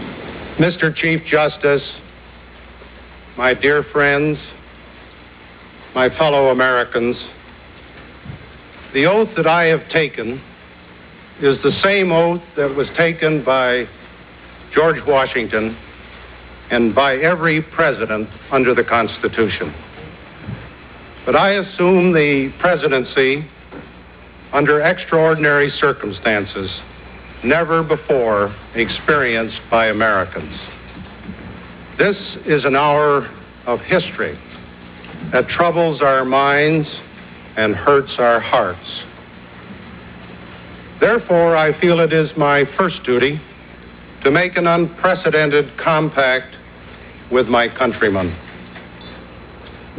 シミュレーション用データ（Ford大統領の就任演説）